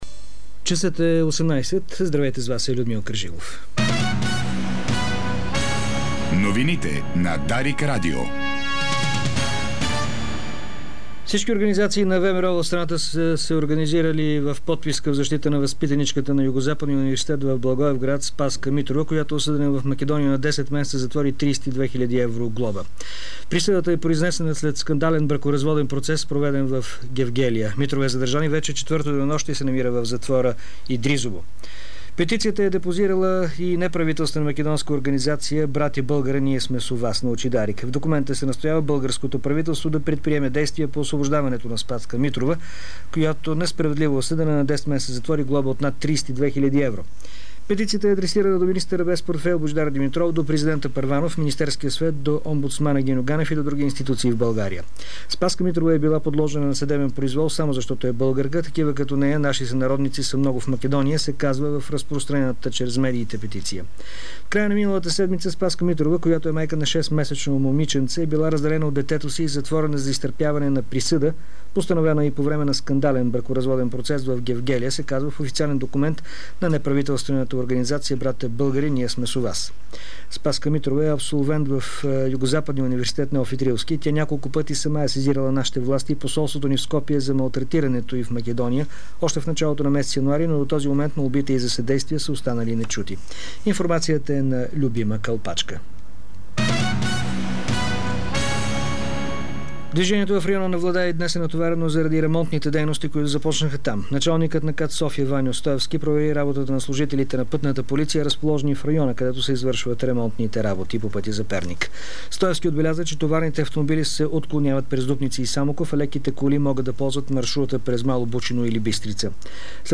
Обзорна информационна емисия - 02.08.2009